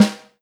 S_snare_Ghost_1.wav